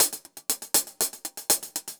Index of /musicradar/ultimate-hihat-samples/120bpm
UHH_AcoustiHatC_120-04.wav